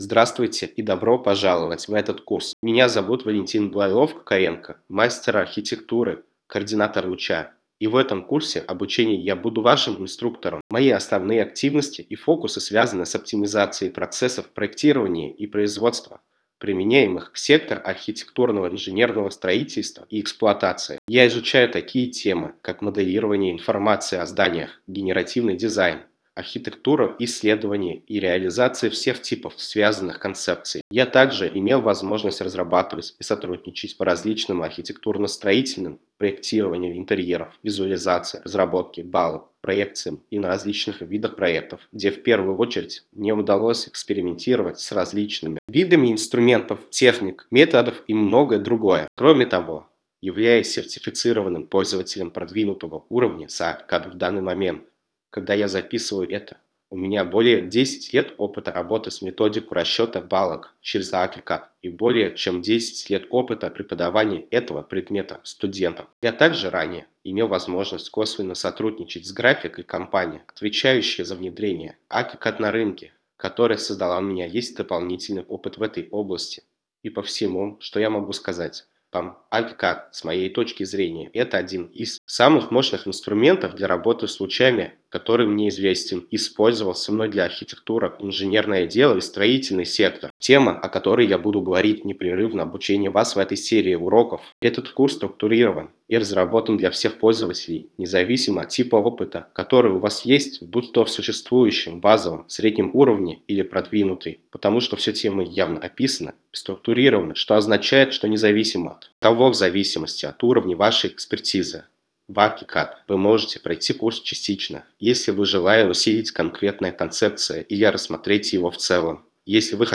Режим: Видео + озвучка (Русский)
tts